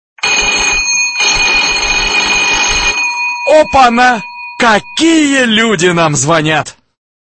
[категория: voice]
Mp3 Голоса из фильмов/рекламы, приколы